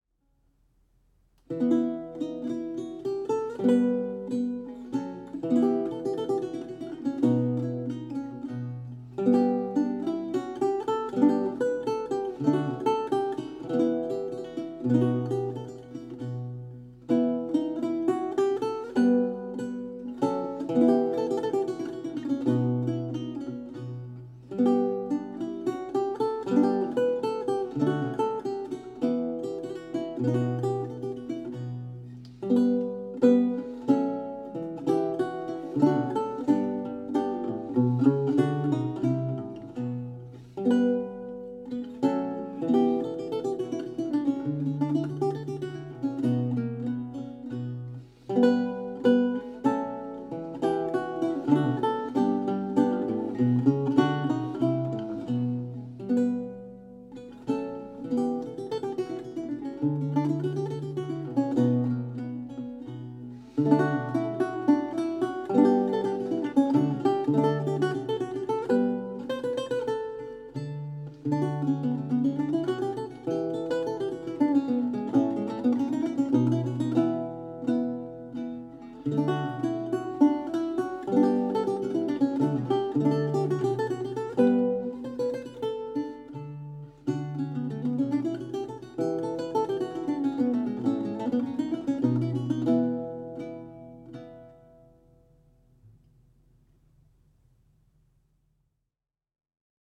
Audio recording of a lute piece from the E-LAUTE project